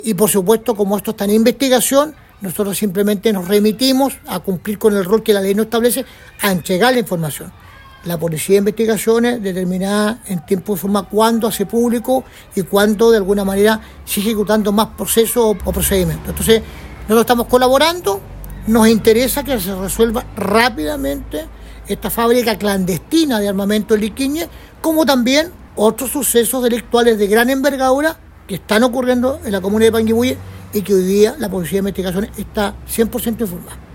Ahora, el alcalde de Panguipulli, Rodrigo Valdivia, sostuvo que por este y otros casos, como municipio colaboran con la entrega de información, esperando que en lo particular el caso se resuelva rápido, así como otros hechos por los cuales la PDI está totalmente informada.
cuna-alcalde-fabrica.mp3